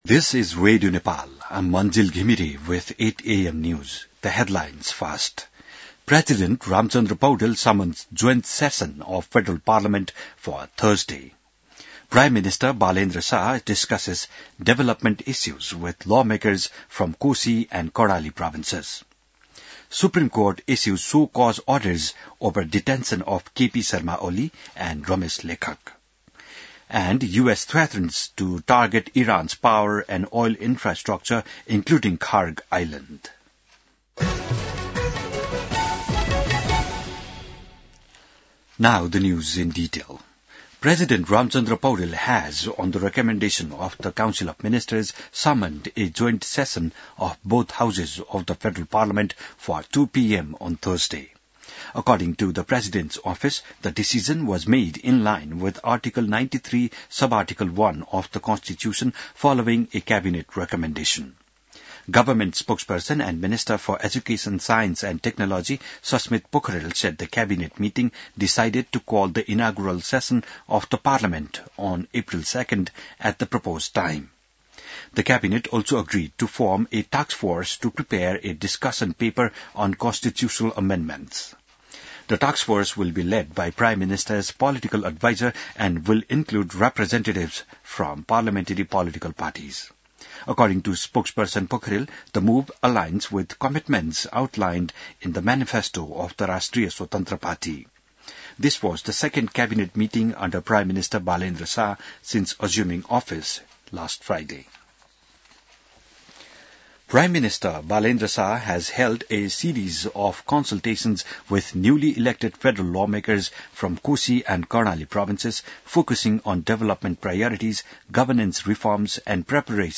बिहान ८ बजेको अङ्ग्रेजी समाचार : १७ चैत , २०८२